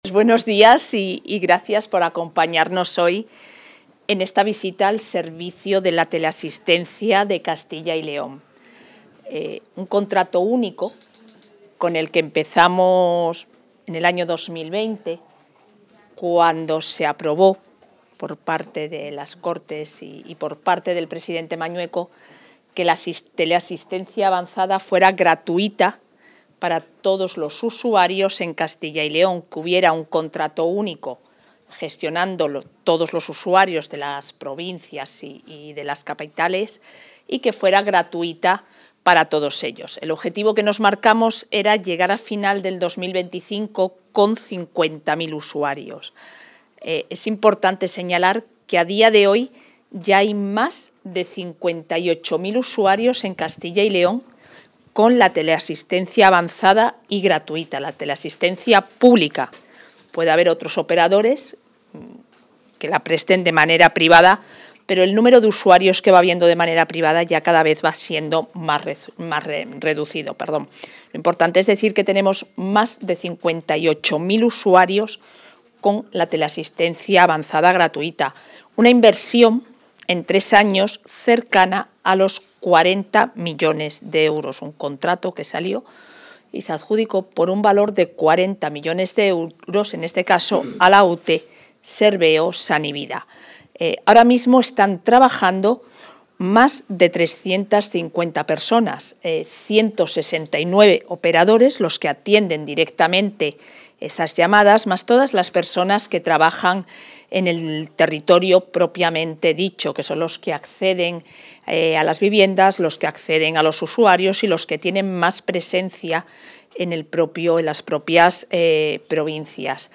Intervención de la vicepresidenta.